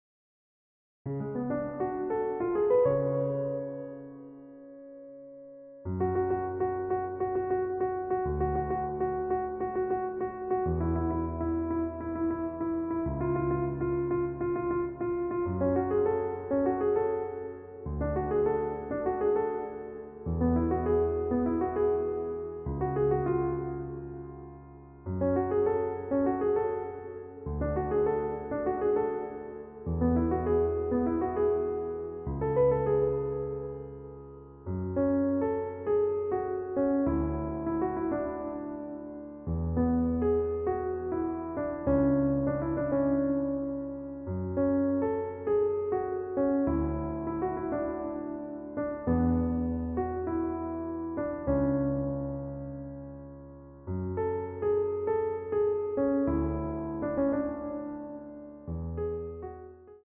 EASY Piano Tutorial